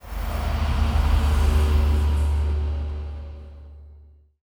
UI Whoosh Notification 2.wav